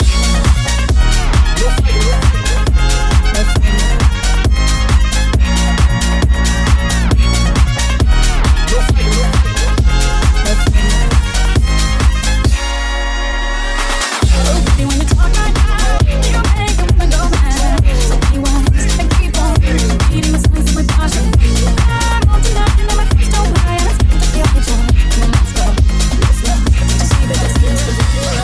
Live mix
house-deep-techno
Genere: deep,house,techno,latin,hit,remix mashup